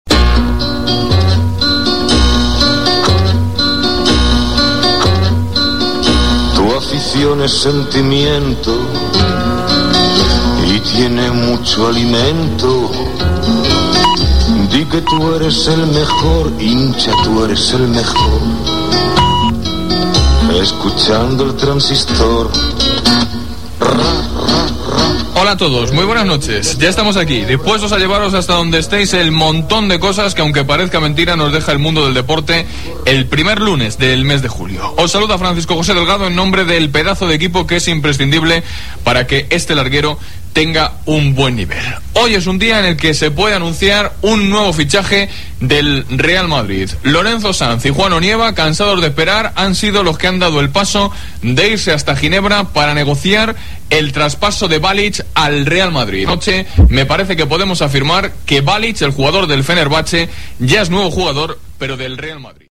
Careta del programa, presentació, nou fitxatge el Real Madrid
Esportiu